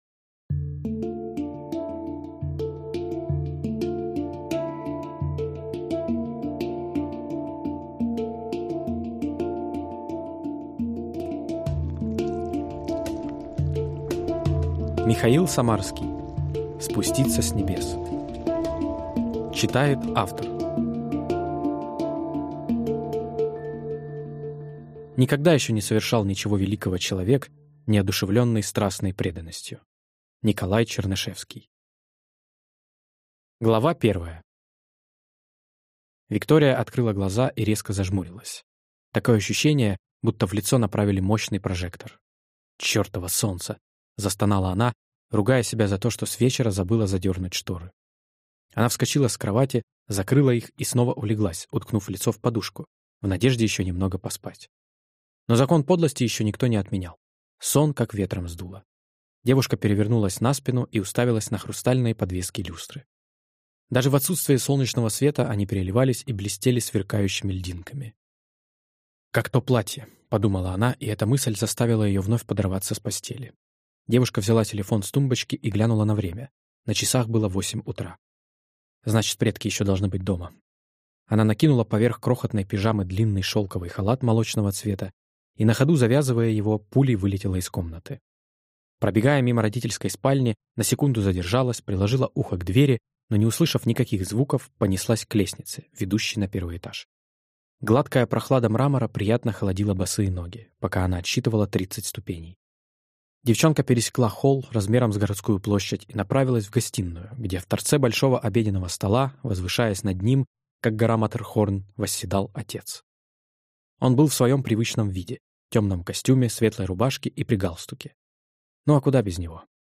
Аудиокнига Спуститься с небес | Библиотека аудиокниг